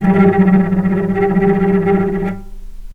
Strings / cello / tremolo
vc_trm-G3-pp.aif